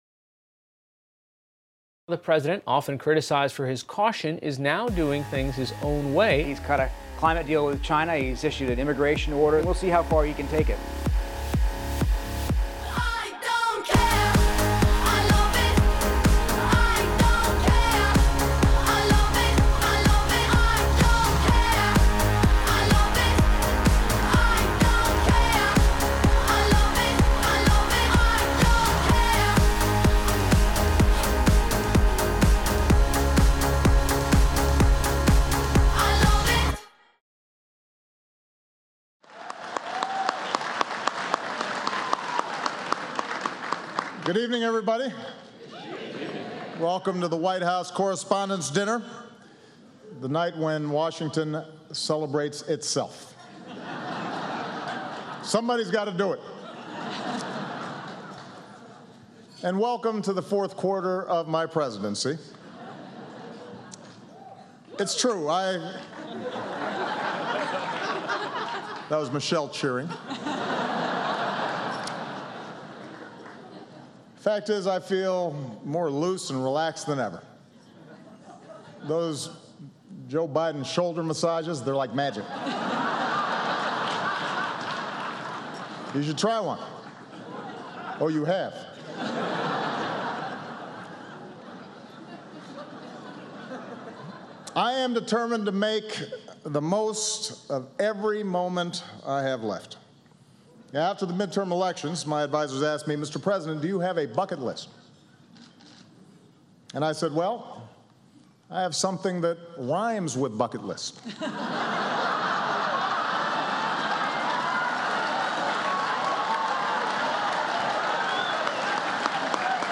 U.S. President Barack Obama speaks at the White House Correspondents' Association Annual Dinner